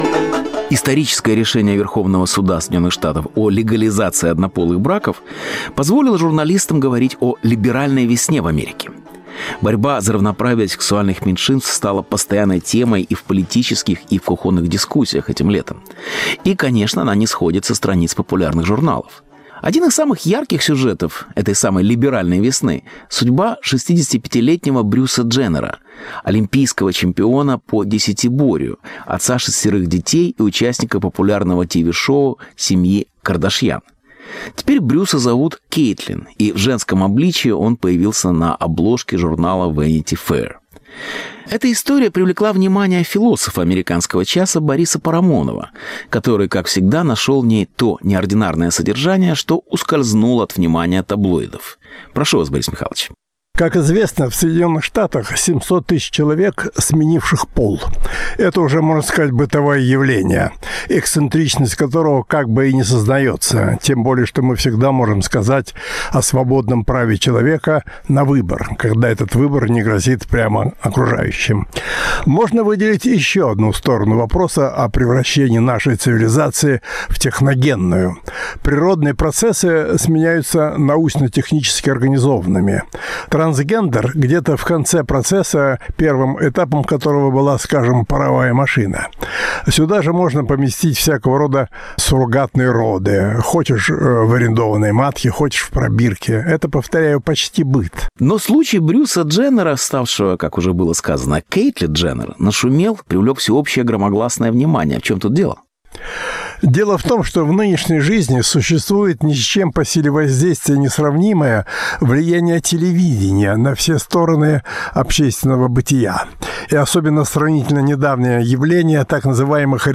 “Трансгендер” как философская проблема. Беседа с Борисом Парамоновым